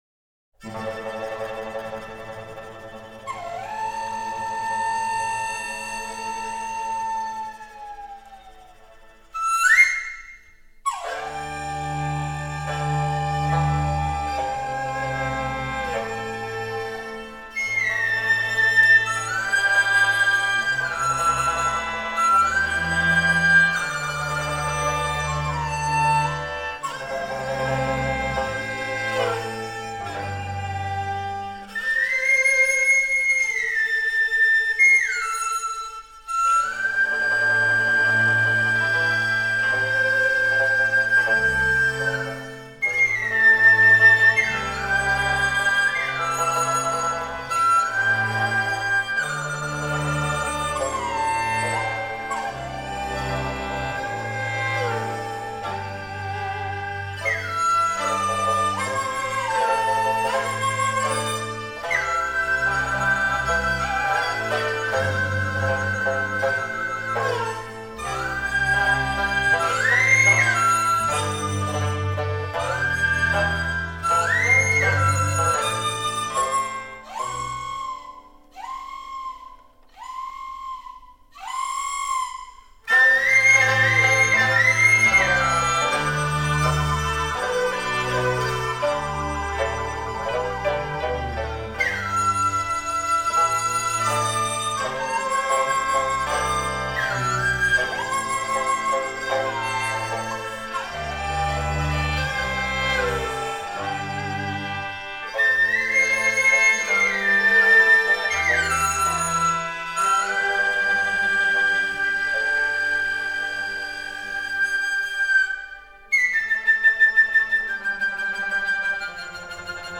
中国吹管乐
梆笛
尾声：用徐缓的速度与辽阔而优美的笛音结束全曲。